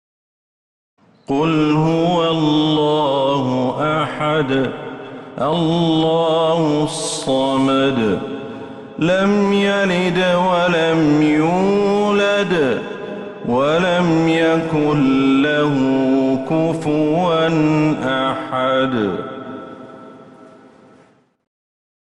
سورة الإخلاص Surat Al-Ikhlas > المصحف المرتل من المسجد النبوي > المصحف